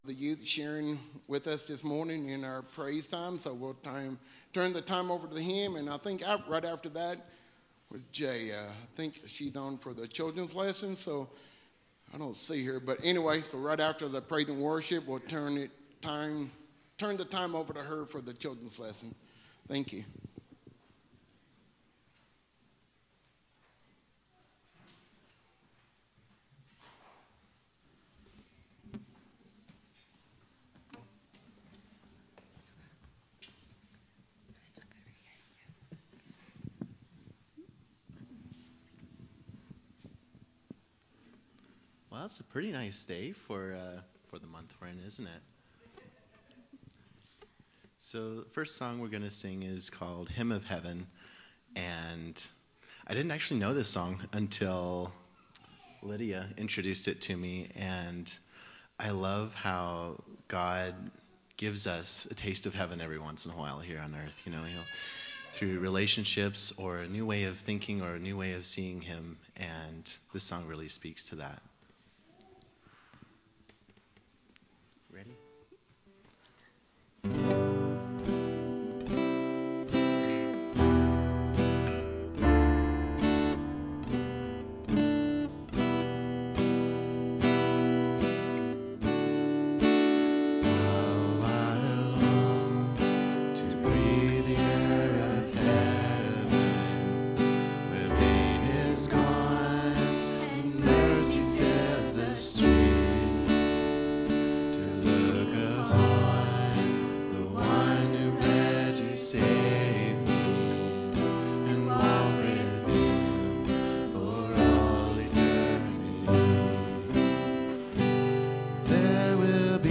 Live Broadcast-Nov 24 2024